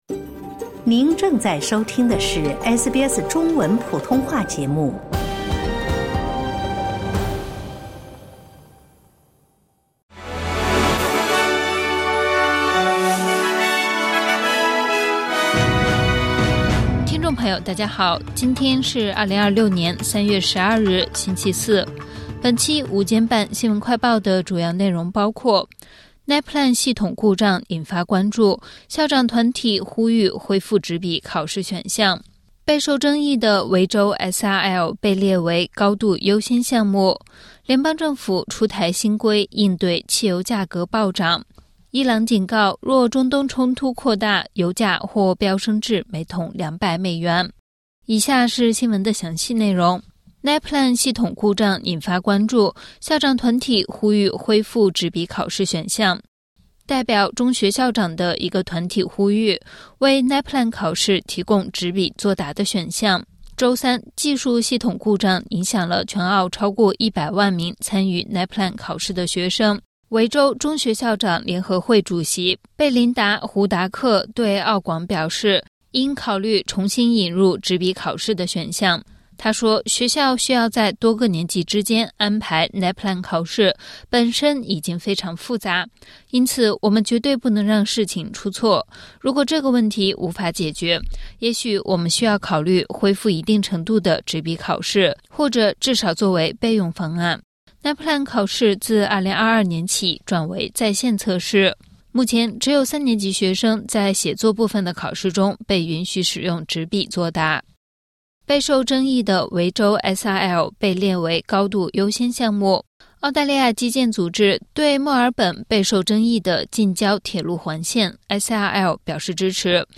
【SBS新闻快报】校长团体呼吁恢复NAPLAN纸笔考试选项